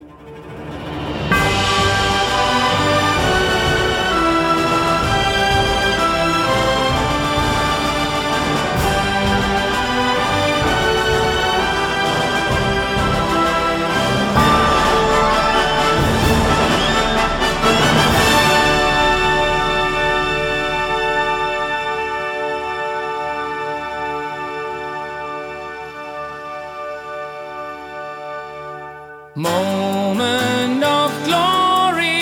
Timp. - Perc. - Harp - Choir - Strings